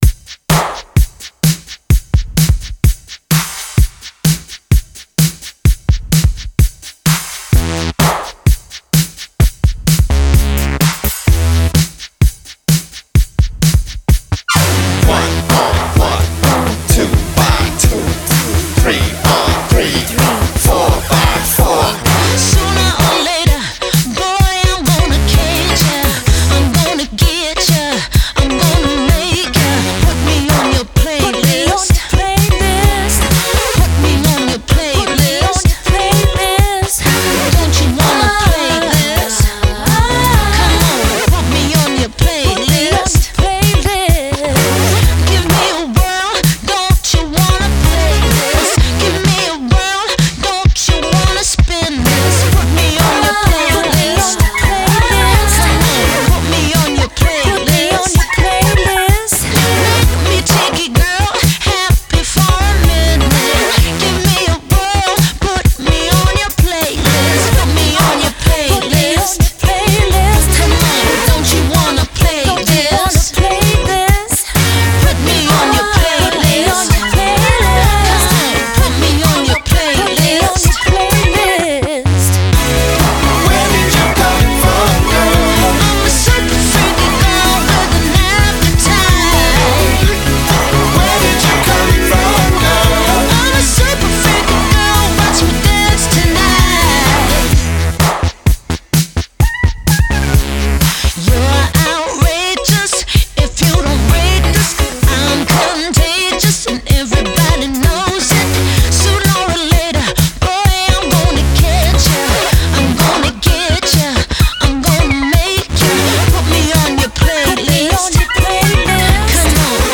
Sydney based duo
with big choruses and sinewy elastic rhythms.